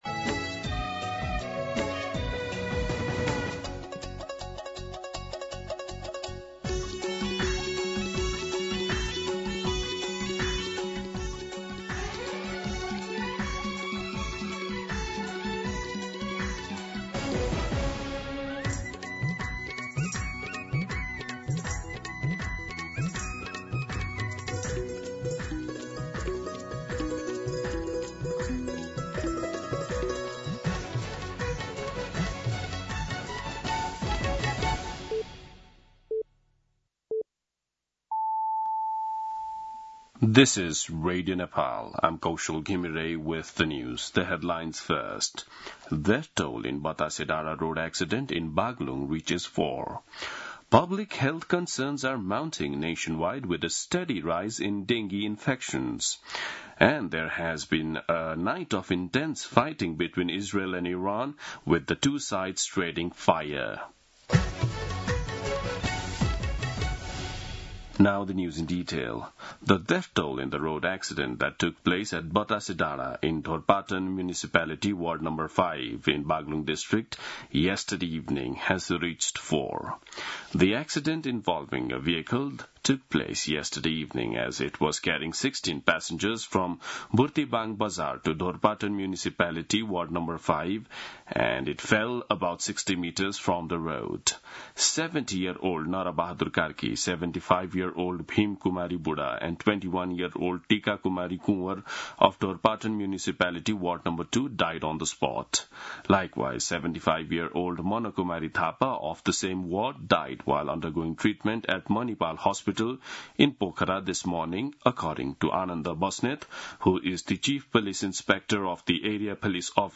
दिउँसो २ बजेको अङ्ग्रेजी समाचार : ३१ जेठ , २०८२
2pm-English-News-02-31.mp3